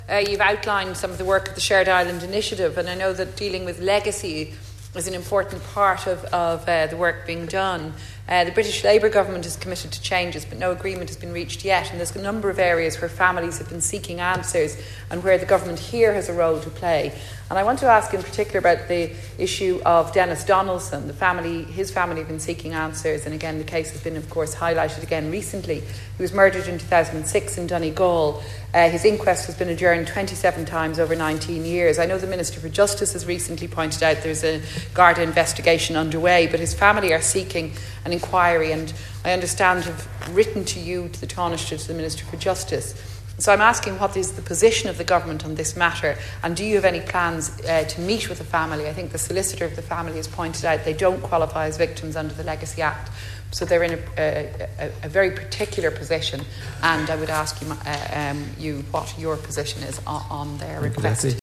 Deputy Ivan Bacik says that this family do not qualify as victims under the new controversial Legacy Act: